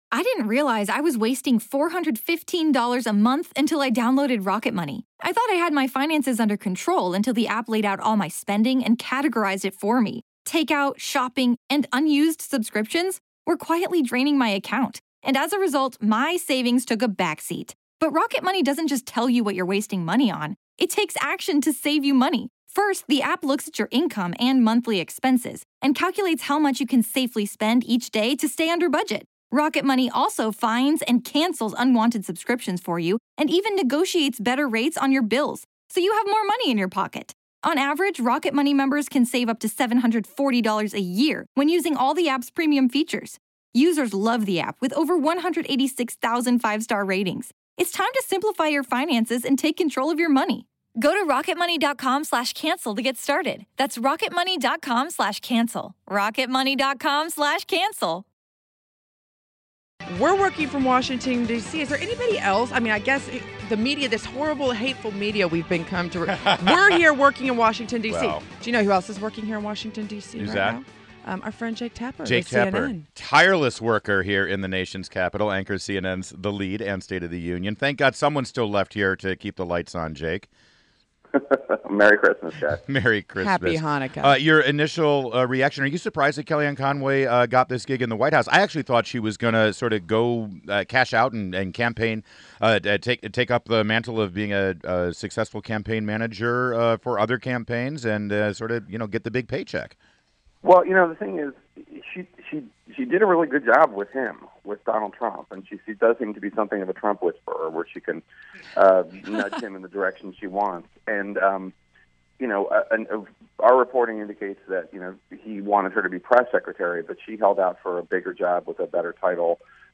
INTERVIEW – JAKE TAPPER – Anchor of CNN’s THE LEAD AND STATE OF THE UNION